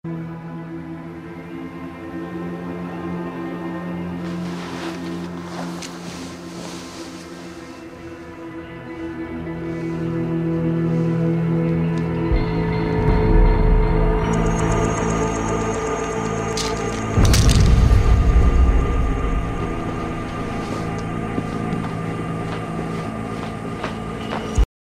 Oui c'est pareil, il n'y a pas de paroles donc c'est la même chose.